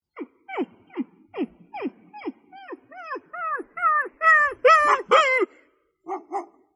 Injured Street Dog Crying With Barking Dogs Reacting Botón de Sonido